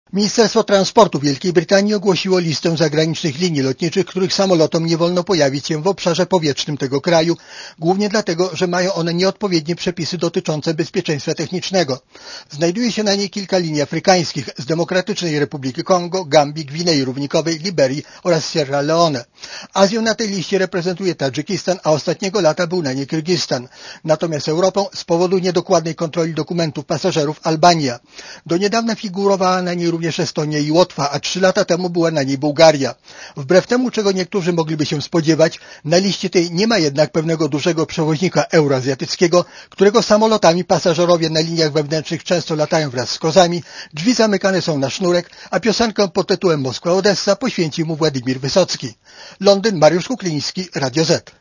Posłuchaj relacji brytyjskiego korespondenta Radia Zet